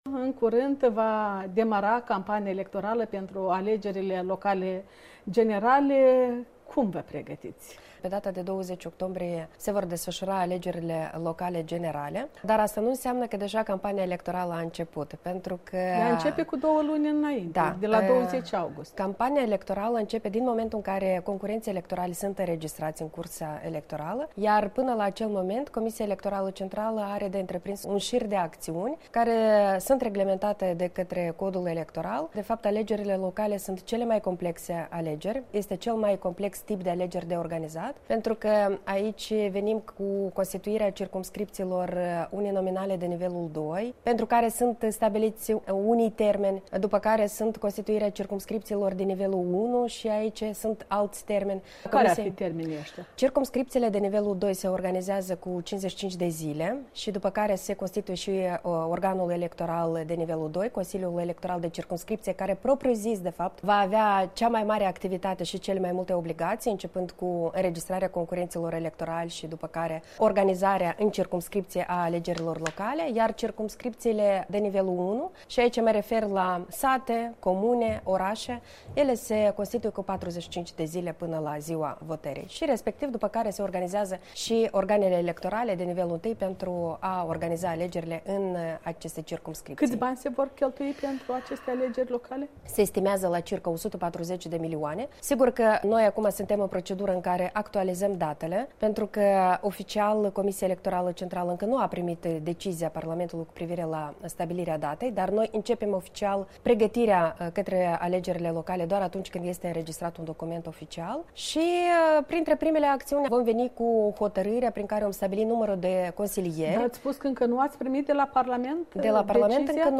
Interviu cu Alina Russu